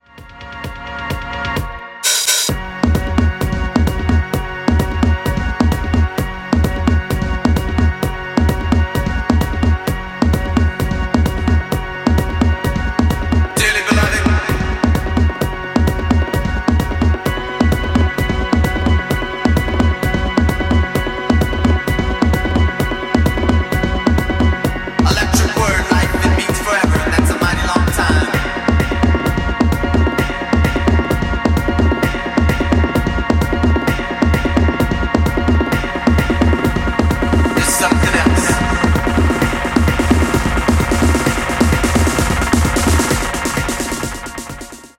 party jams
Electro Techno